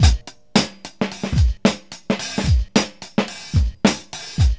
GUT BEAT 110.wav